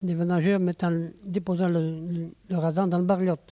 Localisation Saint-Urbain
Catégorie Locution